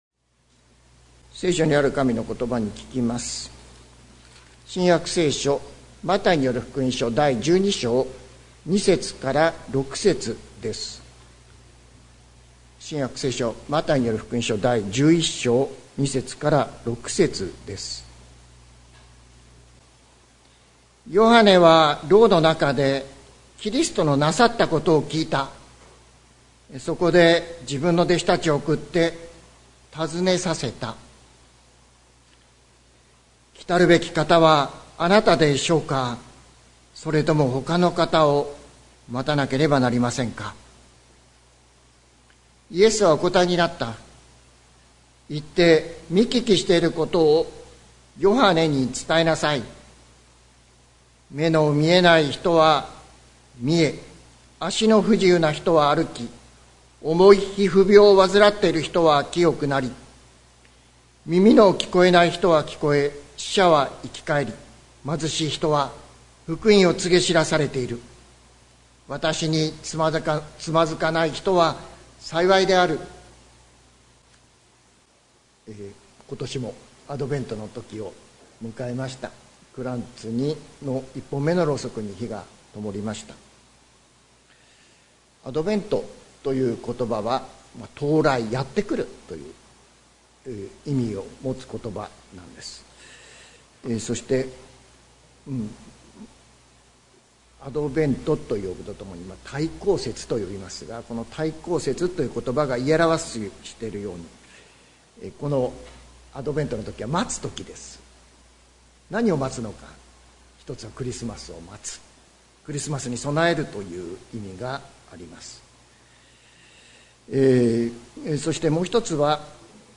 2024年12月01日朝の礼拝「待降節の問い、待降節の答え」関キリスト教会
説教アーカイブ。